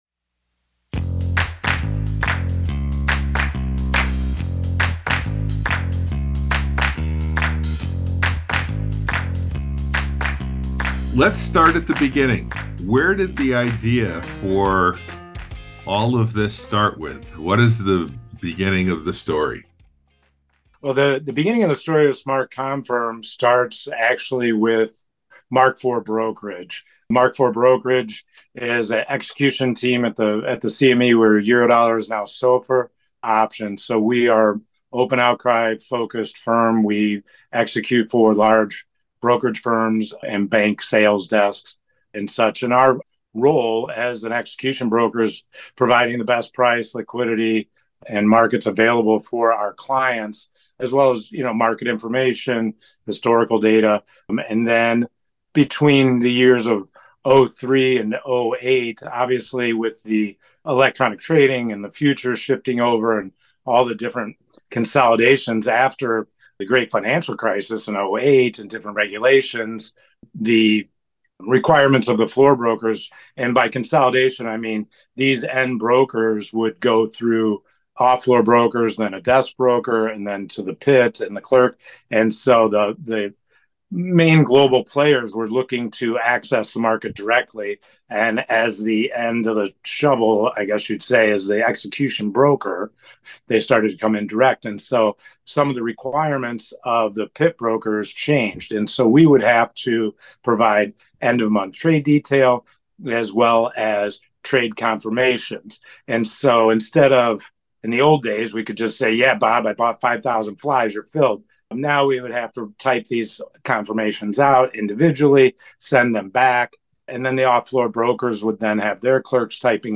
Interview with Founders of Smart Confirm